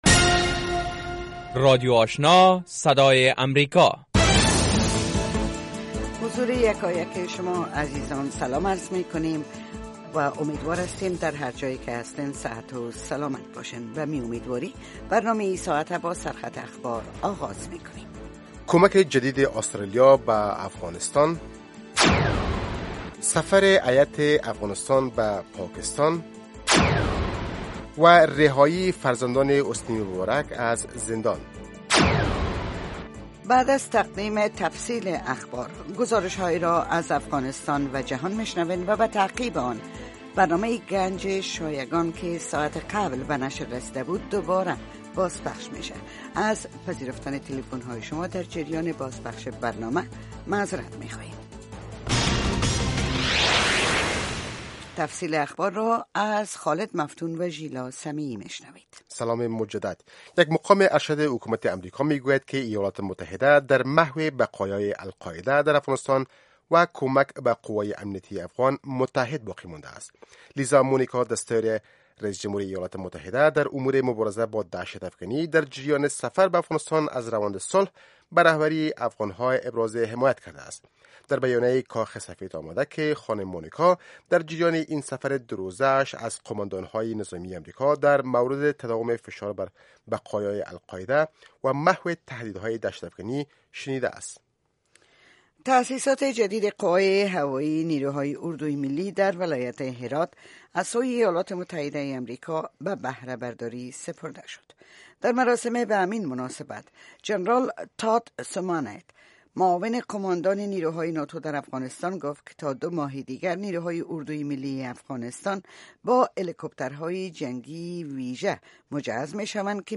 برنامه هفتگی در ساعت ۱۰:۰۰ شب به وقت افغانستان، شامل خبرها، گزارش های افغانستان و جهان و نشر برنامه هفتگی است.